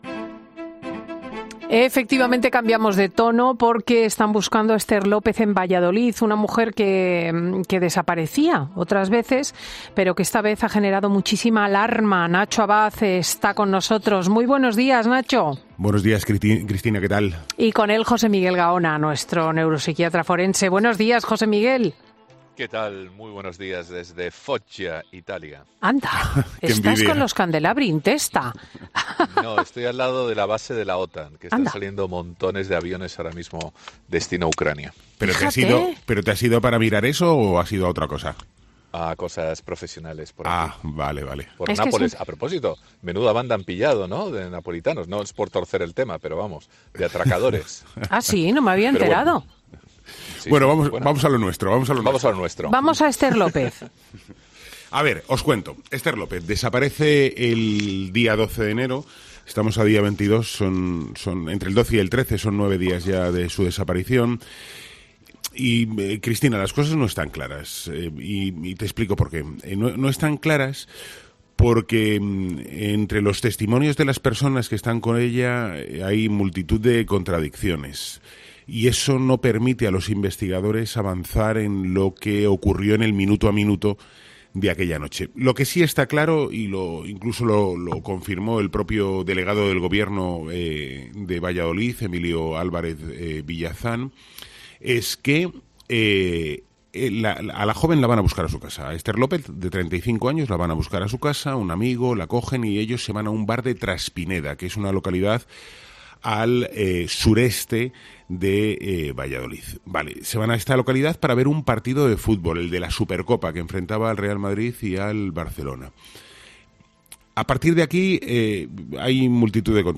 El criminólogo analiza las principales hipótesis y da una última hora de la investigación de la Guardia Civil en Traspinedo (Valladolid)